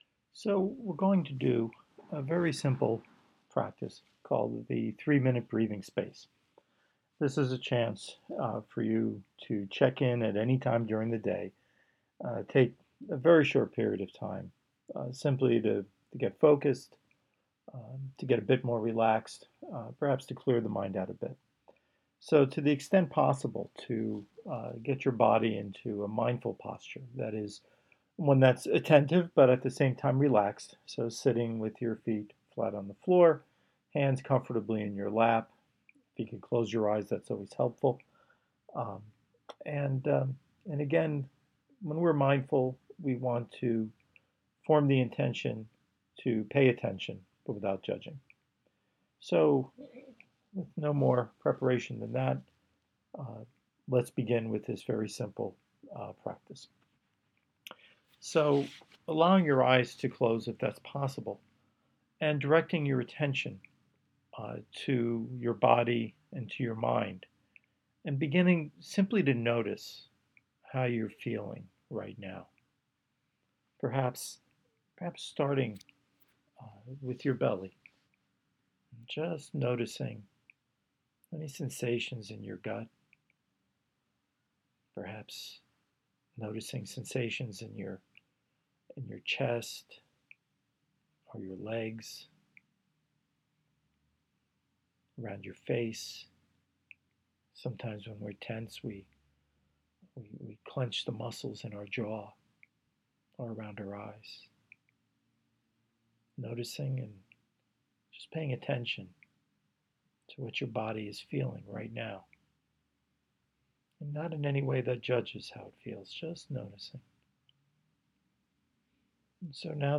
Mindfulness Meditation
three-minute-breathing-space-meditation.m4a